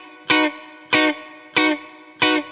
DD_StratChop_95-Bmaj.wav